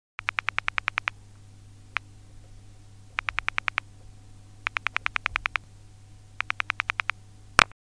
telephonedialpulse.mp3